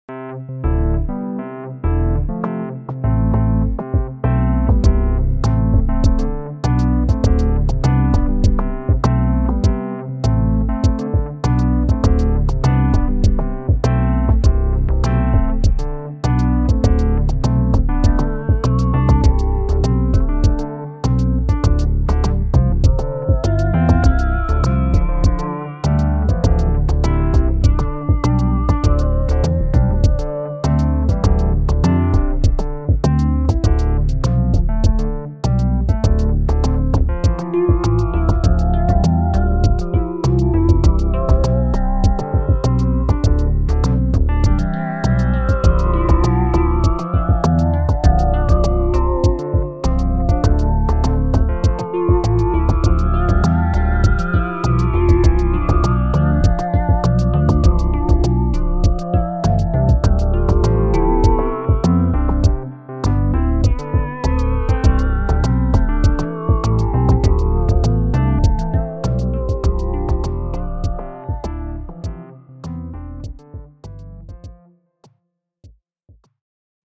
On obtient comme résultat une musique assez inhabituelle, structurée mais sans thème répétitif.
Voici 3 ambiances musicales pour illustrer les capacités de la Société Henon.